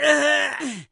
WoW Gnome Death
WoW-Gnome-Death.mp3